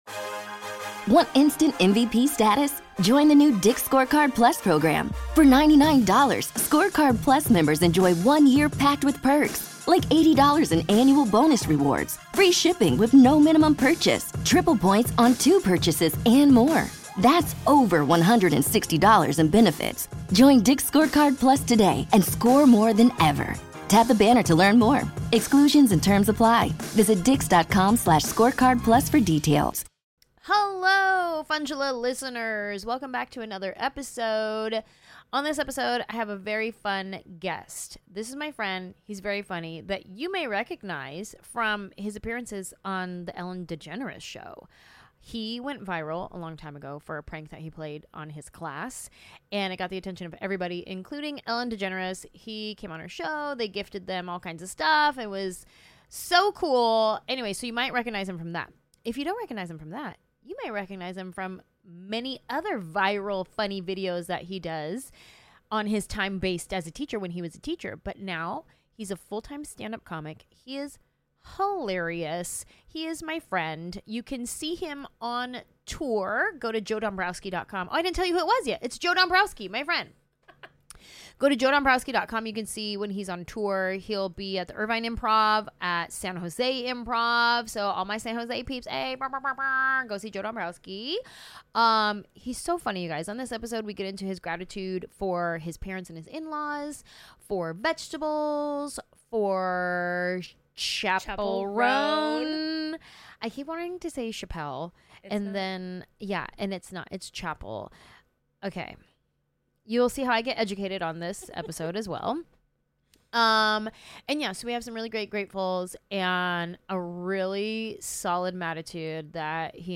Joe Dombrowski, a teacher turned comedian, still can't escape his calling when he teaches Anjelah all about Chappell Roan and the many reasons the Dallas Cowboy Cheerleaders deserve raises. This heartfelt conversation also includes stories about how wonderful Joe's parents are and the joy of persuing your dreams.